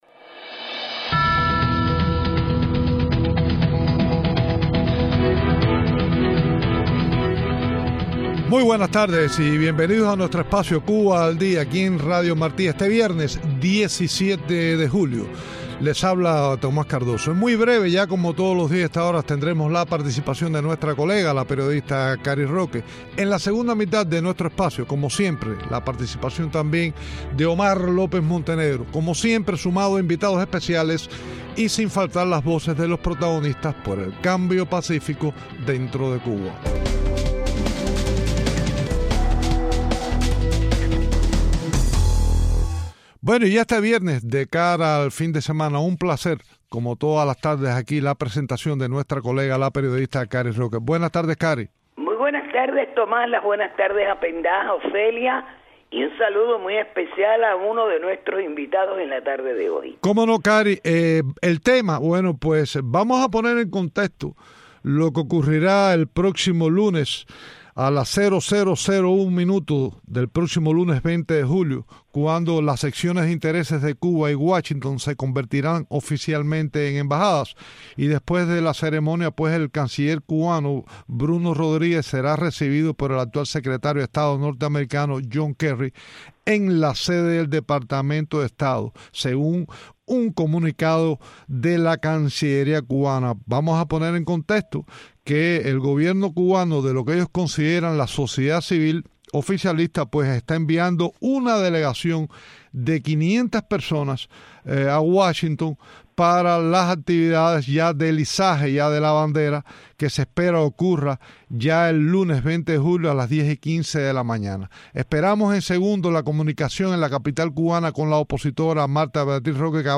Entrevistas con embajador Myles Frechette